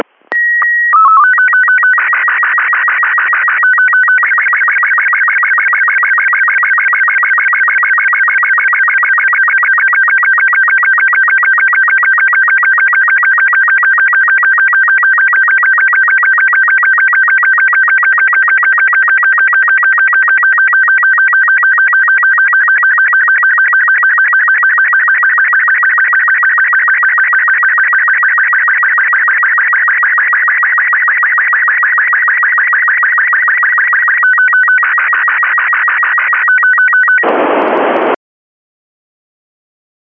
During the morning passes here in Europe I received the SSTV downlink signal from FRAM2HAM on April 2nd 2025 at 06:53 UTC and 08:19 UTC in FM on 437.550MHz using ROBOT36 format.